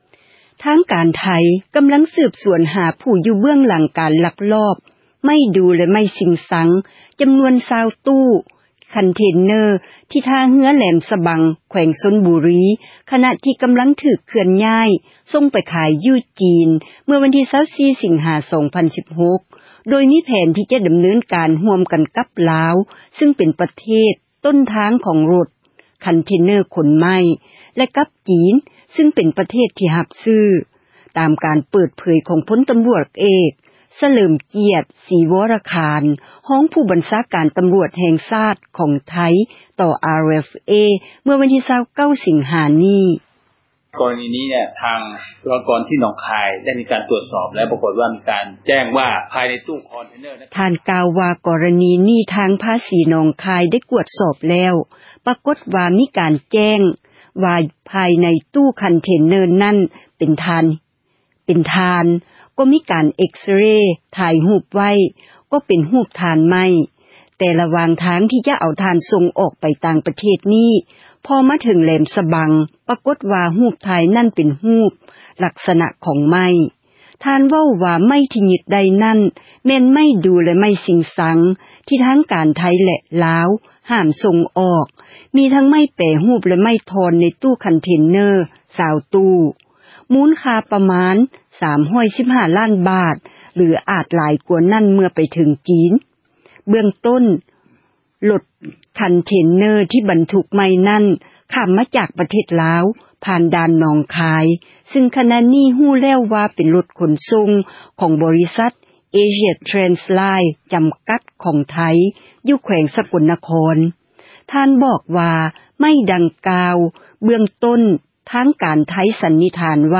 f-police ພົລ ຕໍາຣວດເອກ ສະເຫລີມກຽດ ສີວໍຣະຂານ ຮອງຜູ້ບັນຊາການ ຕໍາຣວດແຫ່ງຊາດ ຂອງໄທ ກ່າວຕໍ່ RFA ເມື່ອວັນທີ 29 ສິງຫາ ນີ້